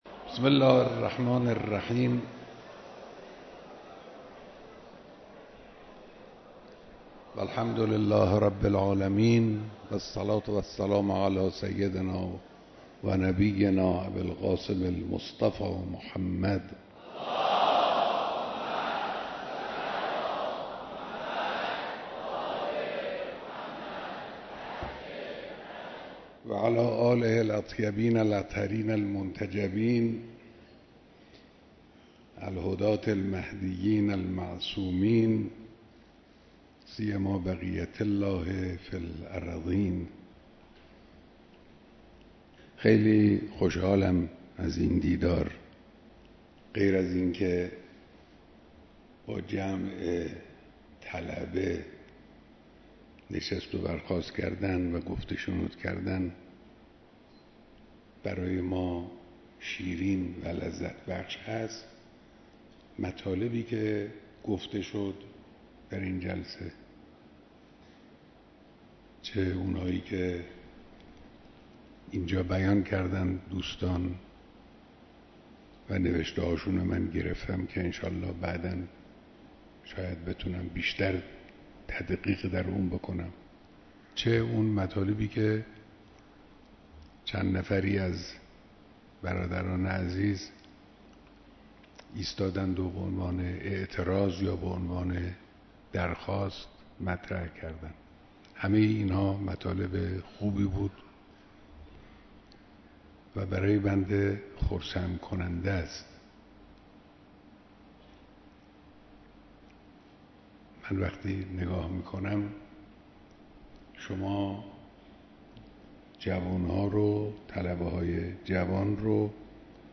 بیانات در دیدار طلاب حوزه علمیه استان تهران